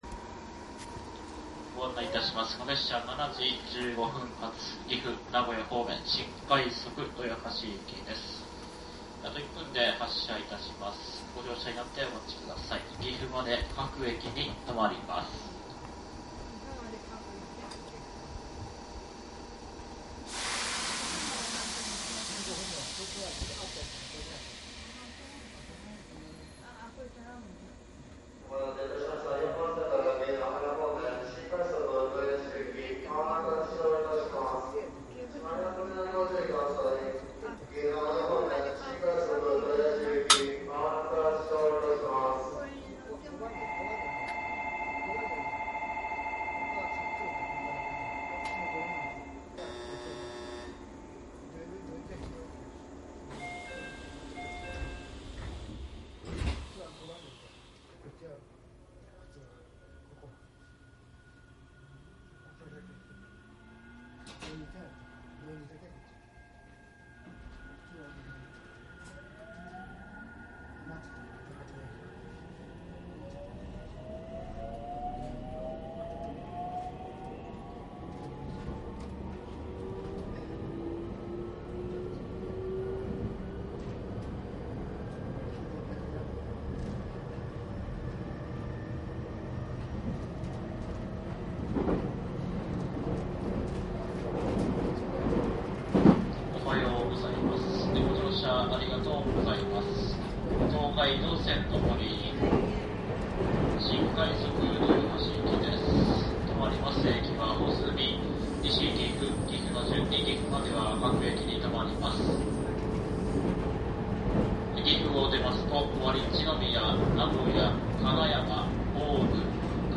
商品説明♪JR東海 313系 新快速 ＣＤ走行音 鉄道走行音 ＣＤ ♪
東海道線上り 313系 新快速 録音 ＣＤです。
■【新快速】大垣→名古屋 クモハ313－7
サンプル音声 クモハ313－7.mp3
マスター音源はデジタル44.1kHz16ビット（マイクＥＣＭ959）で、これを編集ソフトでＣＤに焼いたものです。